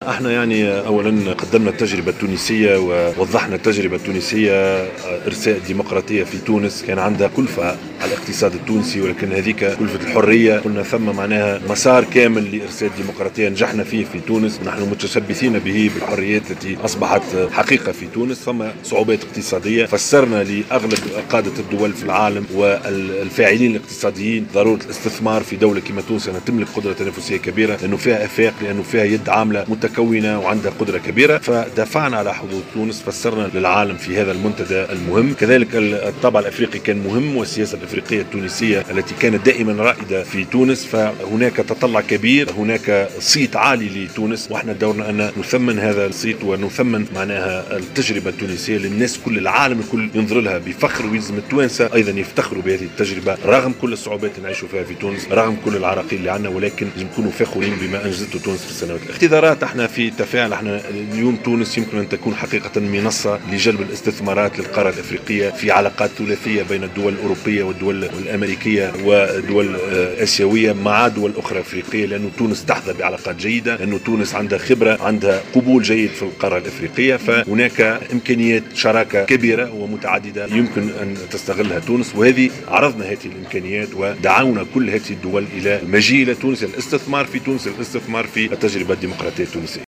أكد رئيس الحكومة يوسف الشّاهد اليوم الأربعاء في دافوس أن تونس قادرة على أن تكون منصة عالميّة لجلب الاستثمارات إلى افريقيا.
وأضاف في تصريح إعلامي انه يعمل خلال هذا المنتدى الدولي على تقديم تجربة الانتقال الديمقراطي في تونس ودعمها من خلال الاستثمارات لتجاوز الصعوبات الاقتصادية، مشددا في هذا الصدد على أهمية الاستثمار في التجربة الديمقراطية التونسية.